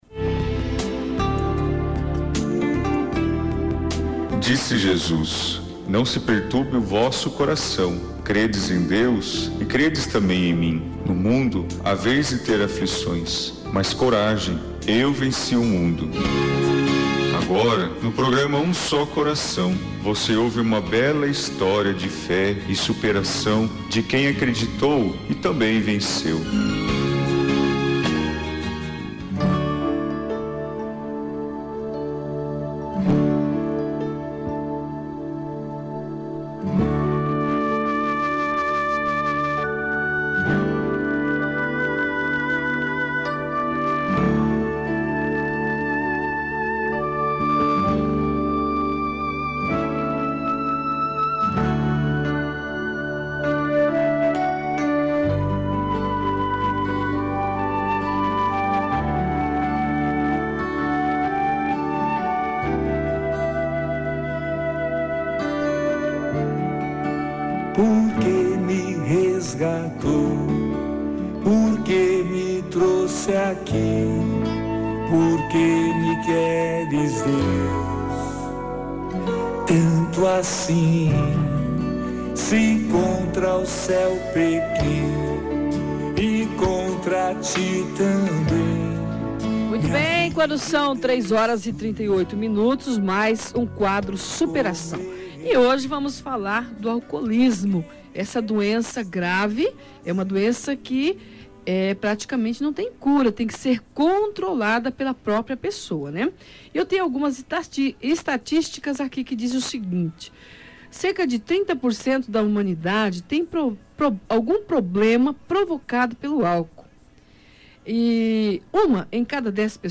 Em testemunho emocionante